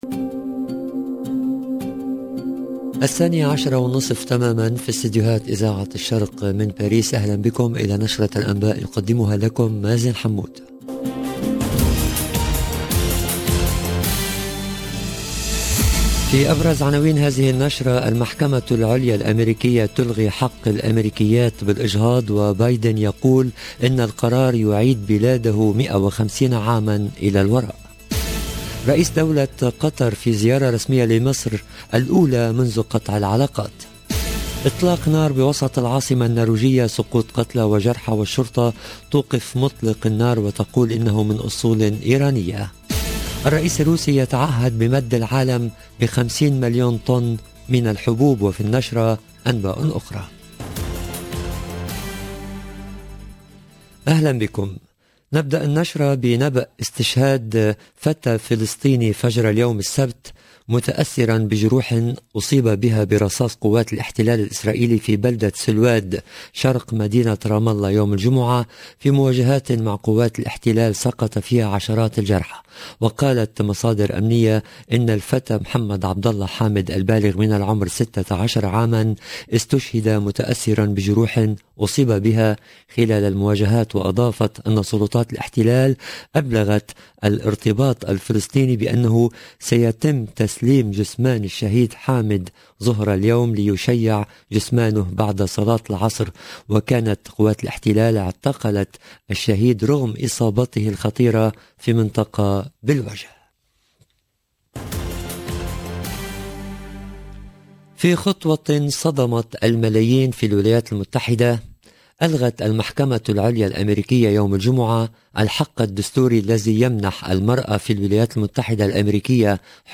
LE JOURNAL EN LANGUE ARABE DE MIDI 30 DU 25/06/22